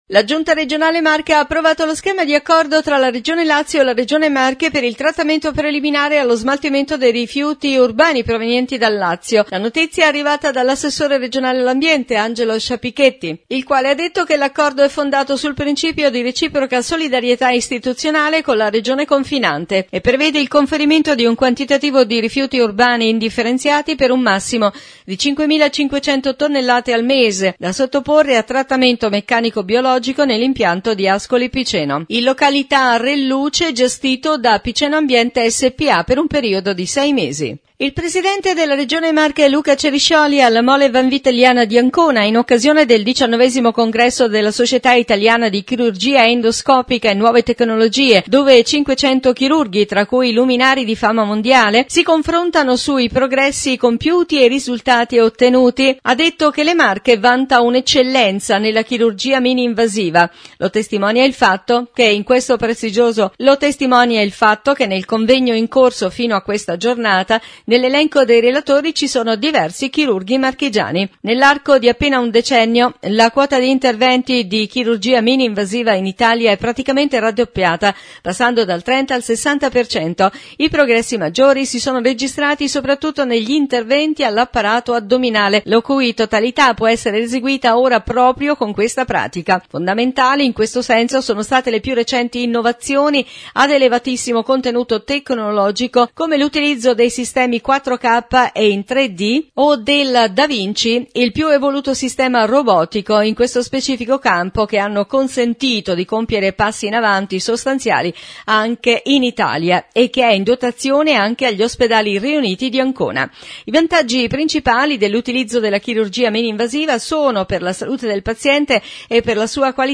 Interviste Luca Ceriscioli – Presidente Regione Marche Alessia Morani – Sottosegretario al Mise